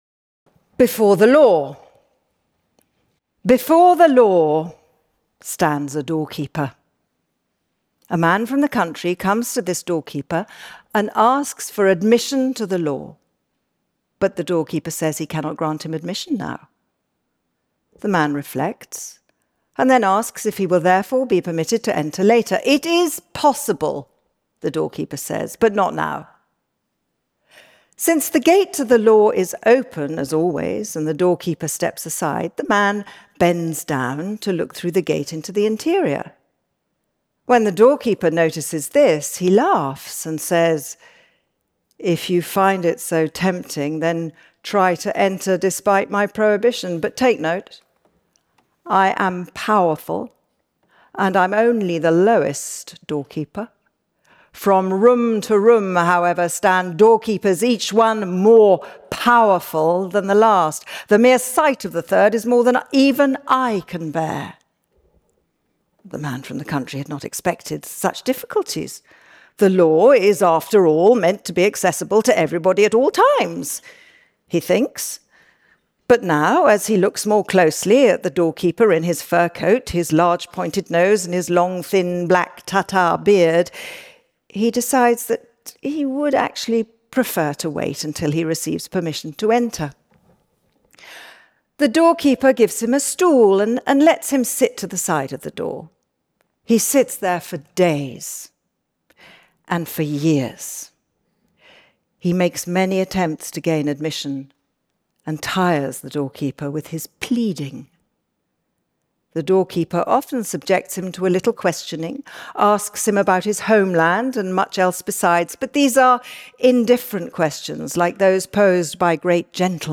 Kristin Scott Thomas Reads Kafka